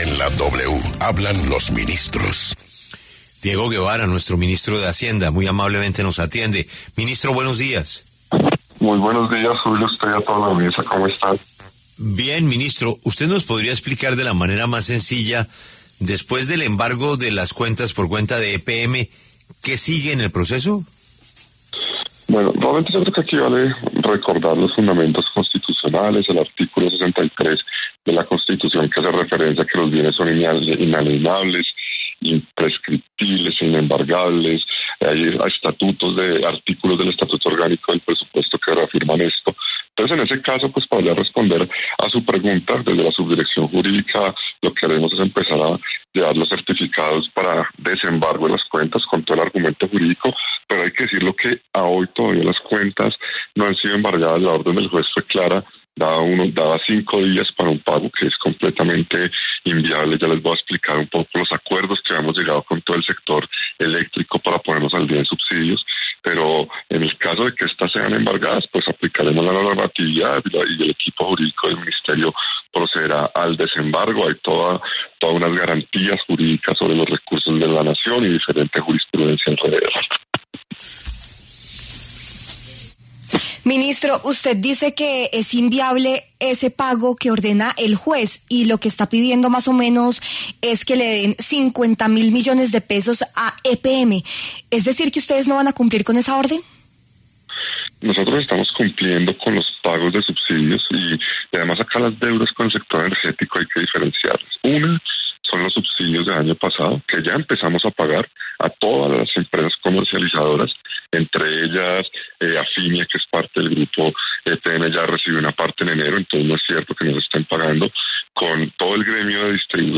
El ministro de Hacienda, Diego Guevara, habló en W Radio sobre la orden de un juez de la República de embargar las cuentas del Gobierno por las deudas que tienen con EPM y aseguró que la deuda que tienen con las empresas de energía “existen” y las van “a pagar”.